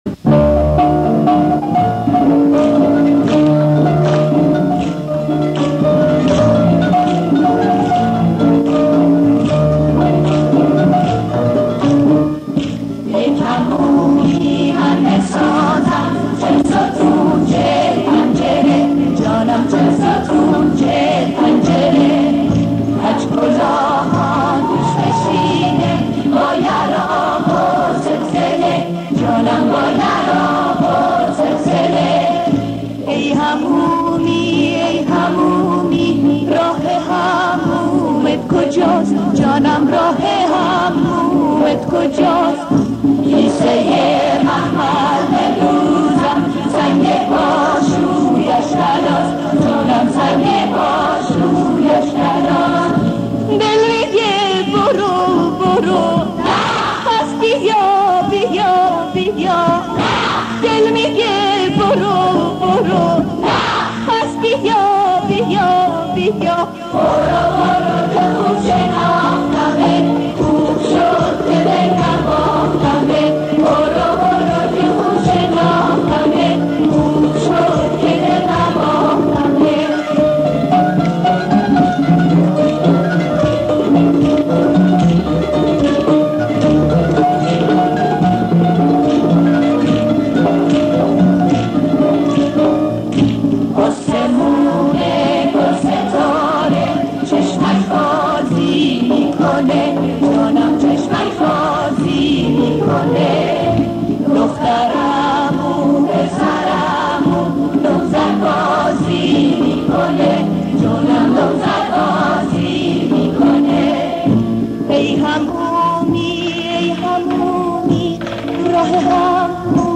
ترانه سرا: (فلکلور)
.این ترانه در برنامه صبح جمعه رادیو اجرا گردیده است